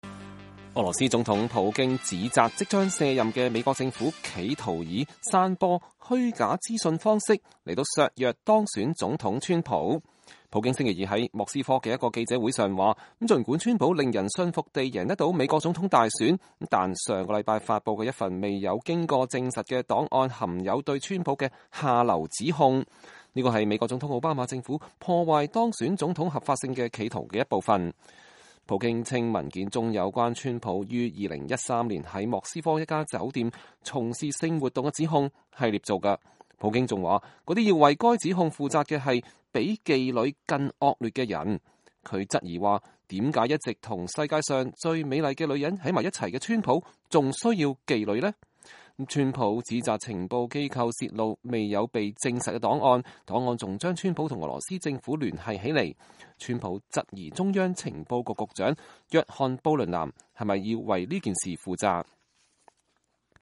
俄羅斯總統普京1月17日在莫斯科的記者會上發言